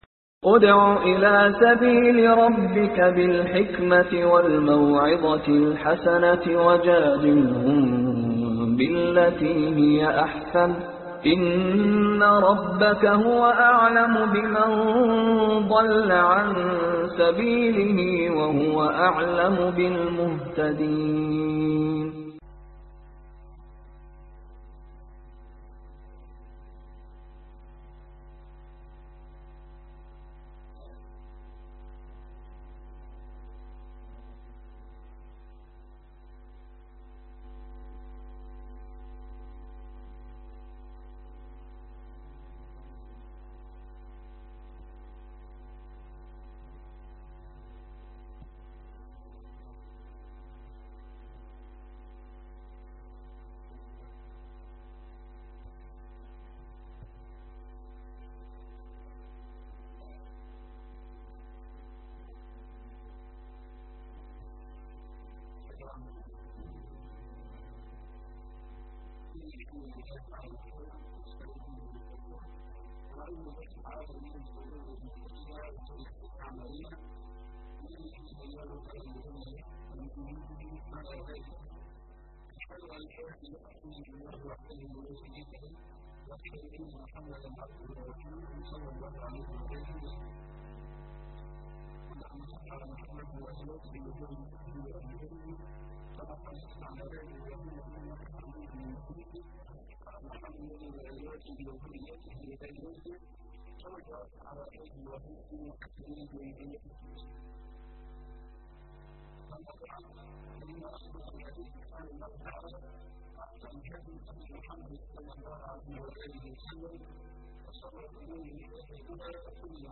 المشتاقون للشريعة - محاضرات مسجد الهداية